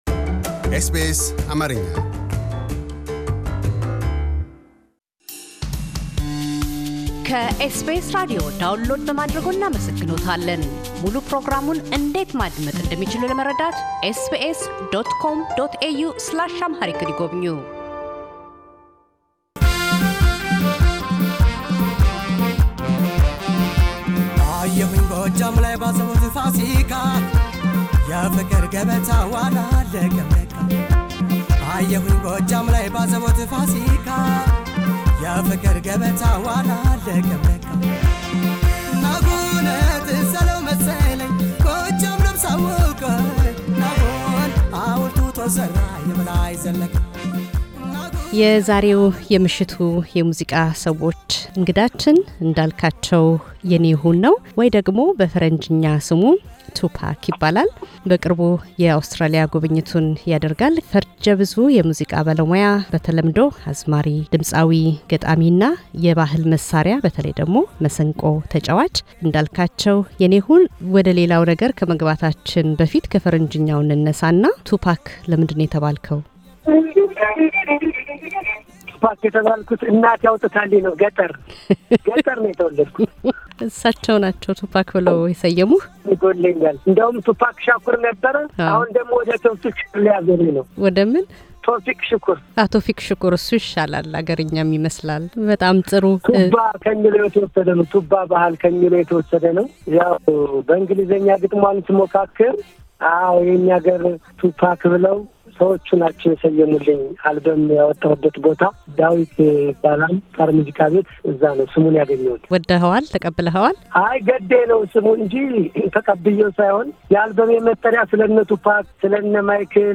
ቃለ ምልልስ -ድምፃዊ